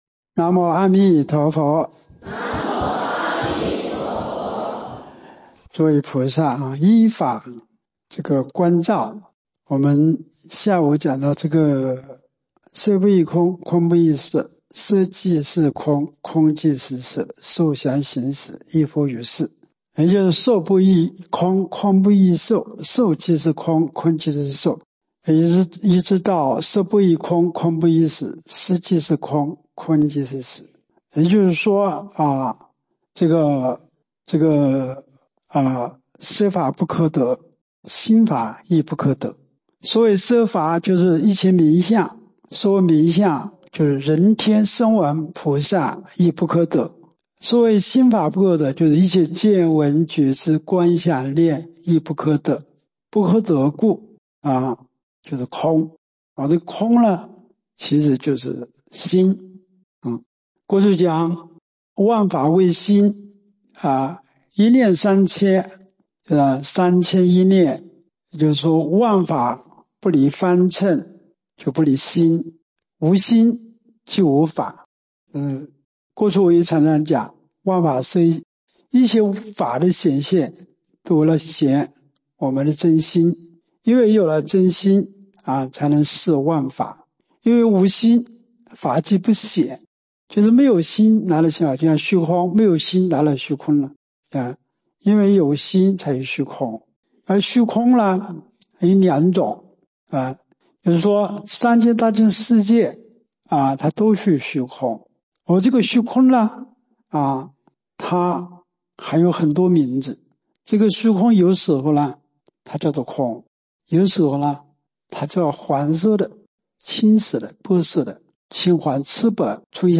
无量寿寺2025年春季极乐法会念佛开示（般若与净土）之六